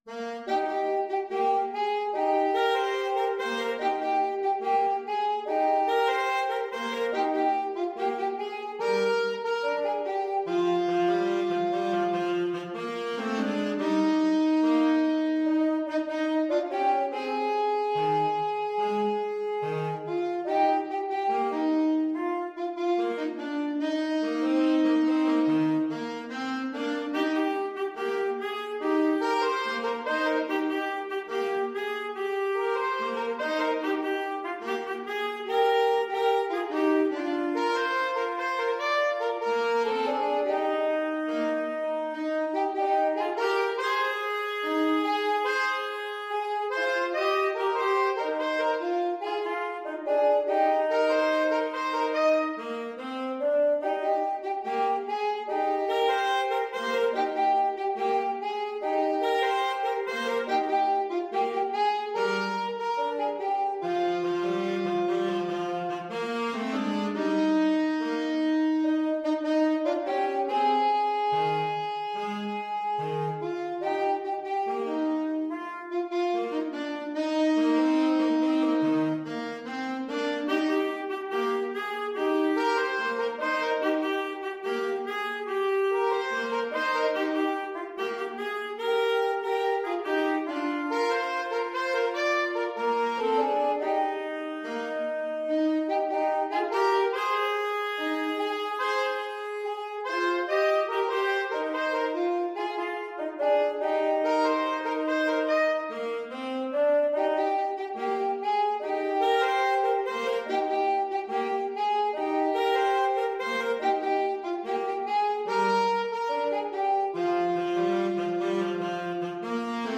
Alto SaxophoneTenor Saxophone
Gentle two in a bar = c. 72